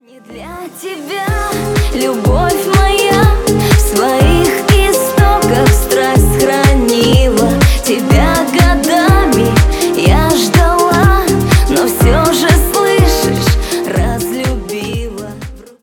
Музыка » Шансон » Викторина